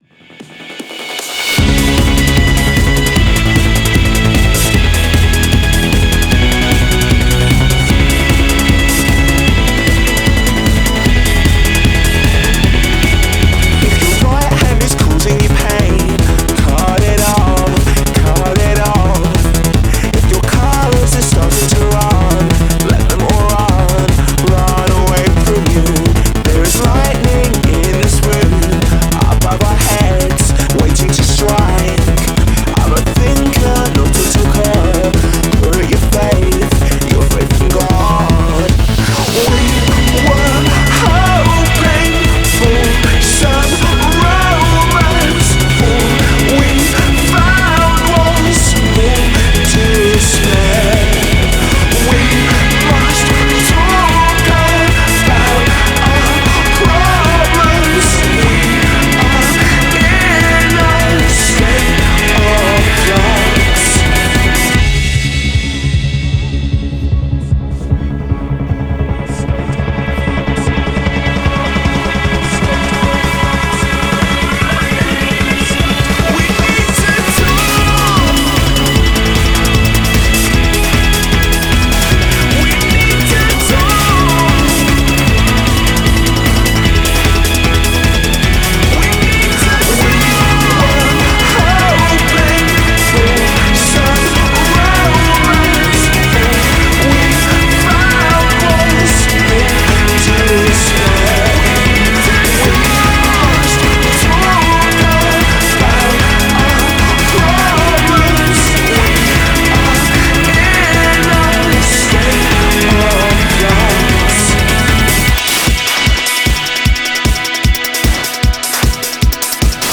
BPM152
MP3 QualityMusic Cut